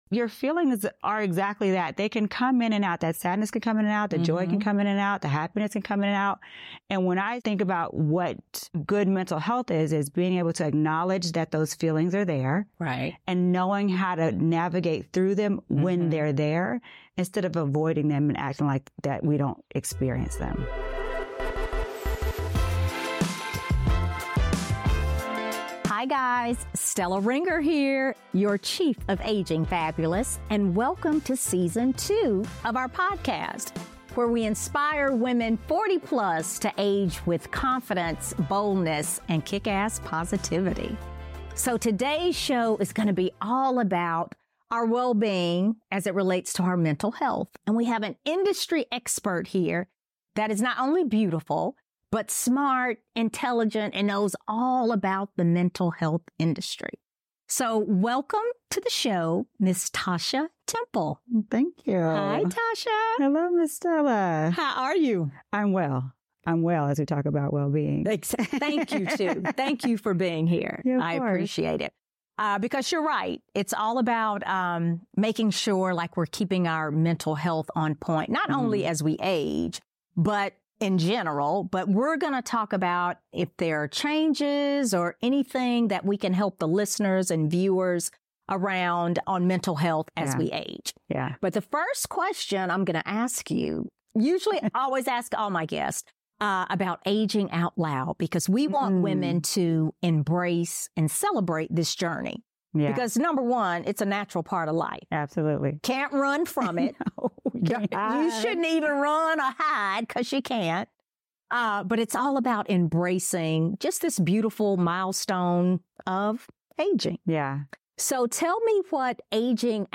Today’s conversation explores the importance of mental health and wellbeing as we age.